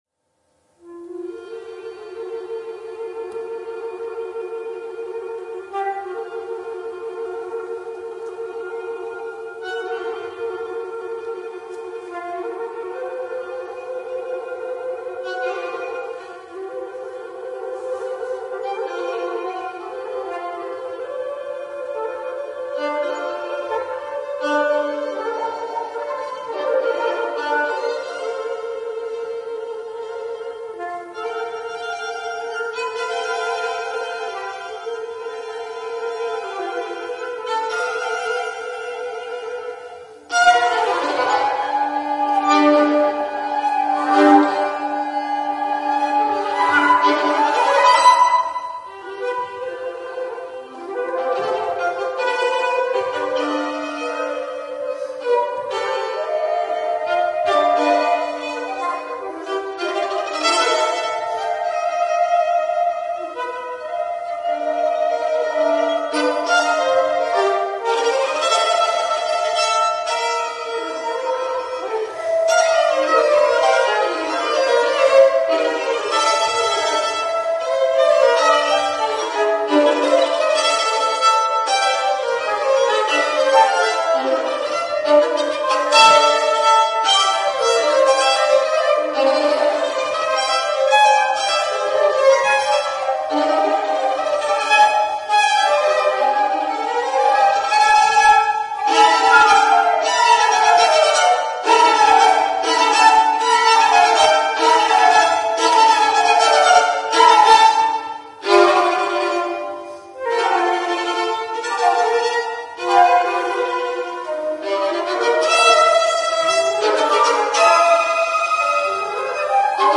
per flauto e violino / for flute and violin (1997/2003)
flute
violin